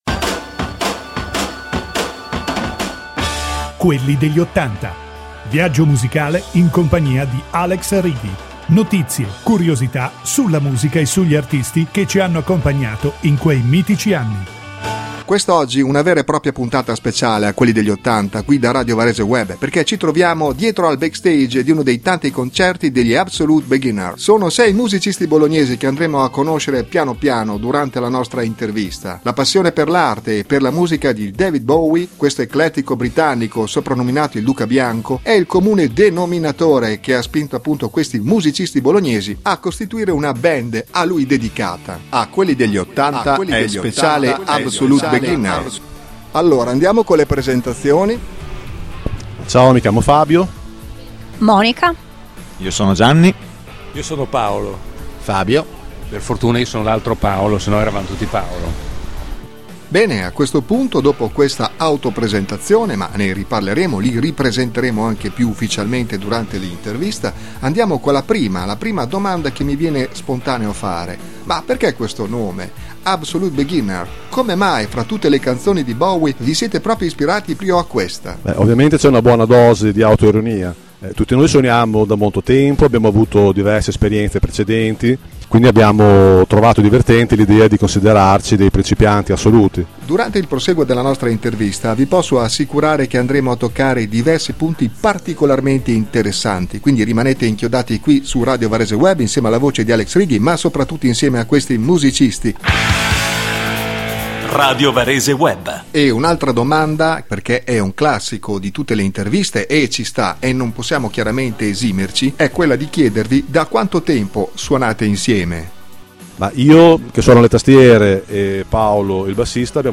L’intervista di radiovareseweb